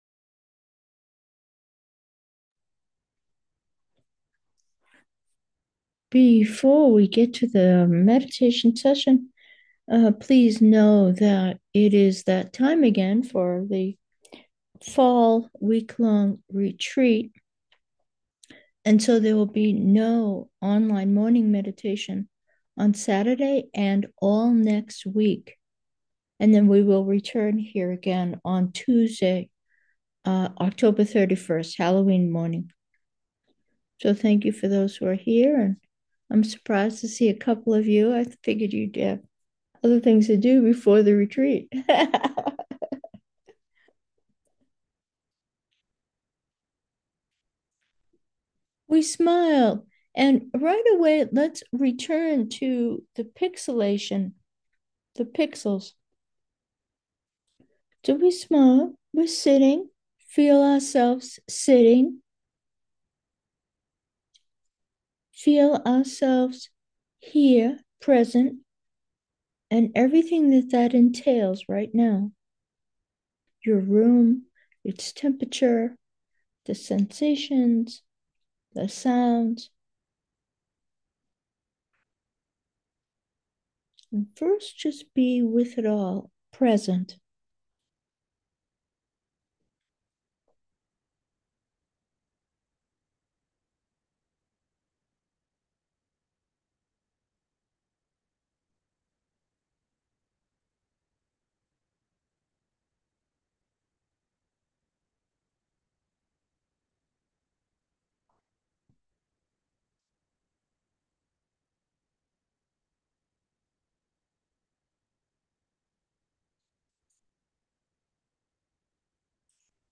Meditation: pixels of subtlety